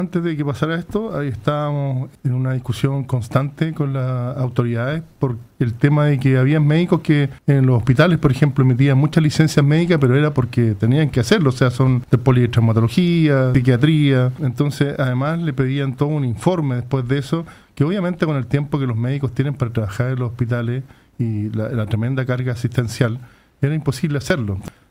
En conversación con Radio Paulina